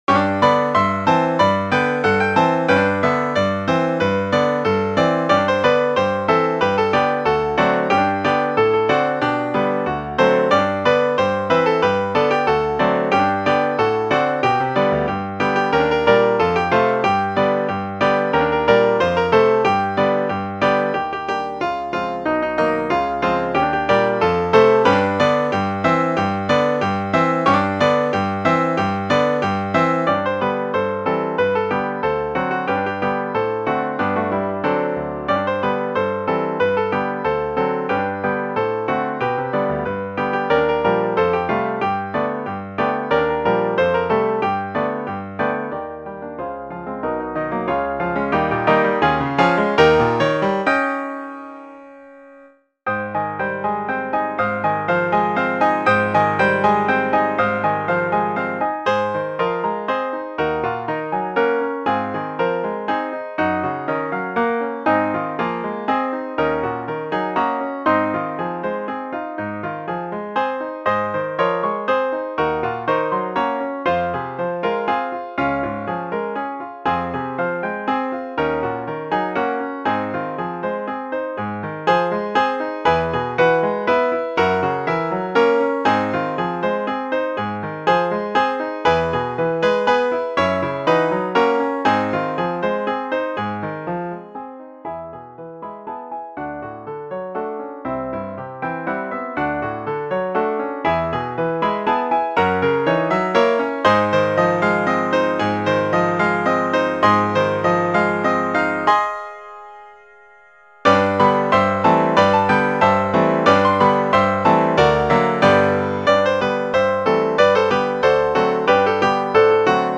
Voicing: SA,Piano 4-h